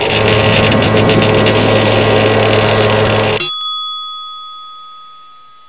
assets/pc/nzp/sounds/machines/packapunch/upgrade.wav at 668acaf60fc4d59791d7153b81449cd3ed8bb1a4